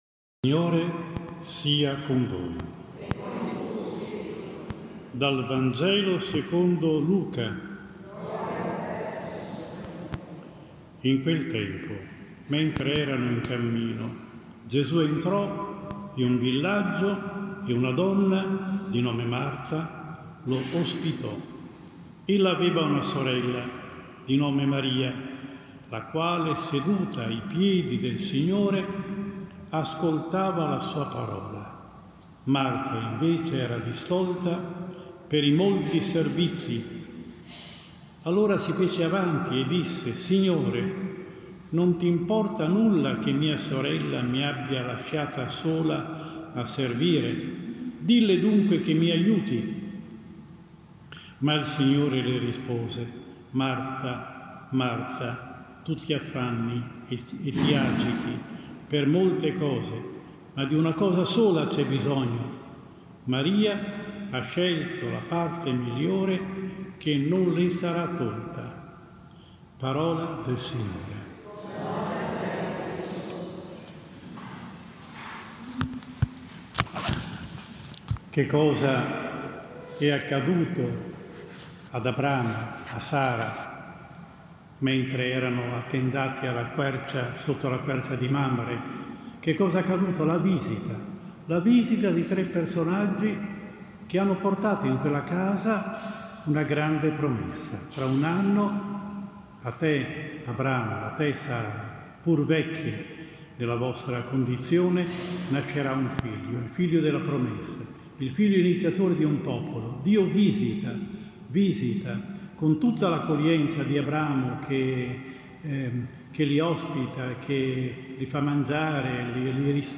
17 Luglio 2022 XVI DOMENICA, Tempo ordinario, anno C: omelia